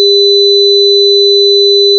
The red circles show the values of |H| at the digital frequencies corresponding the analog frequencies of 400 hz and 4000 hz, which are the components in x(t).